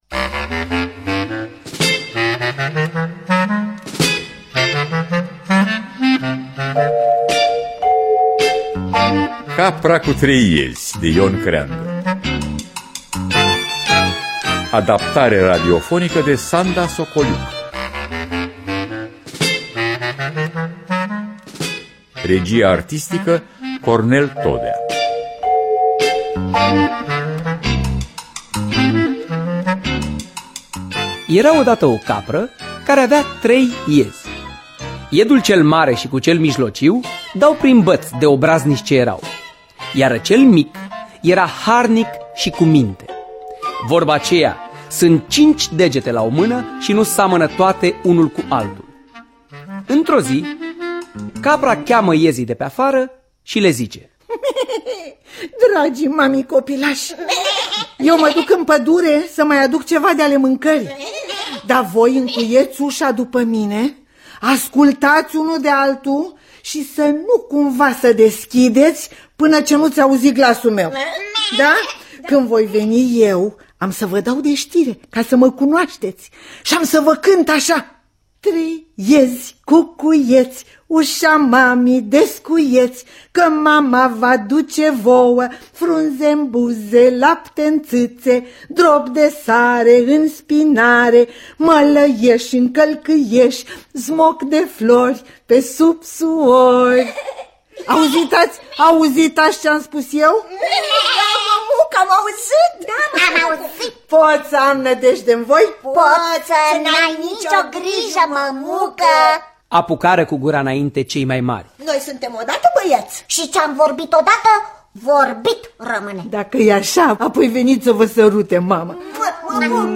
Capra cu trei iezi de Ion Creangă – Teatru Radiofonic Online
Adaptarea radiofonică